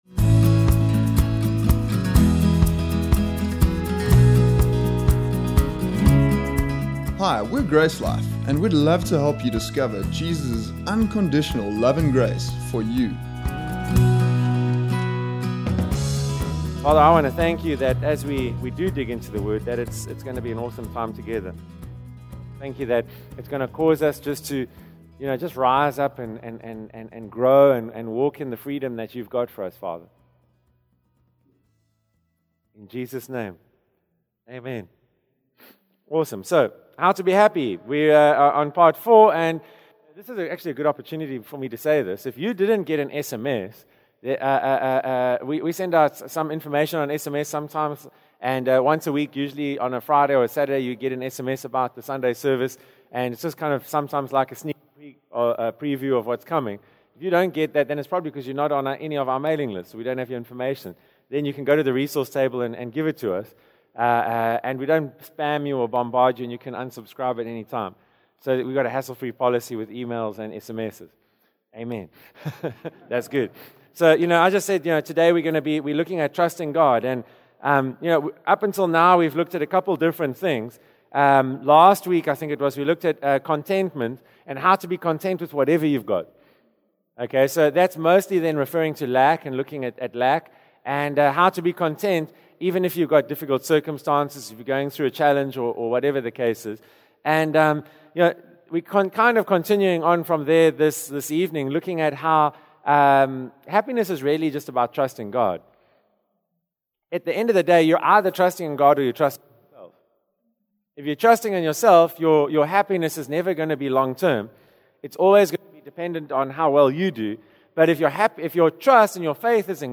Through this practical teaching series